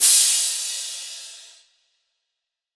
Crash [Southside].wav